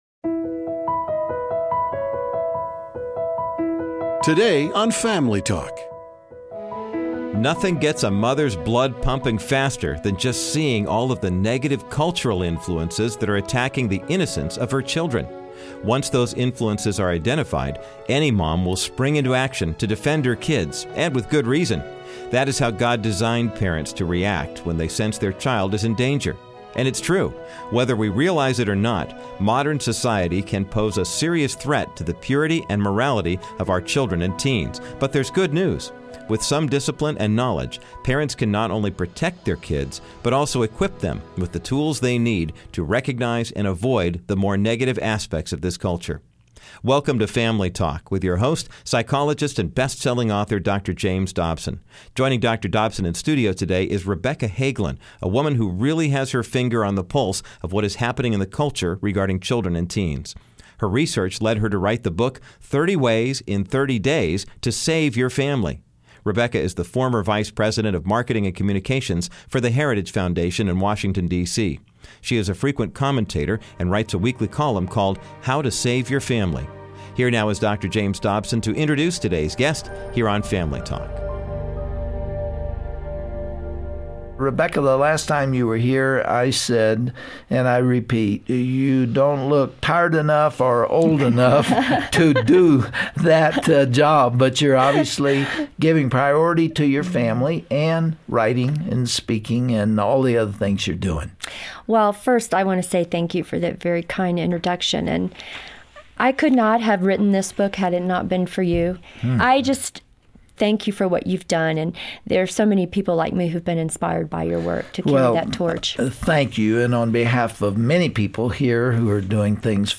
Host Dr. James Dobson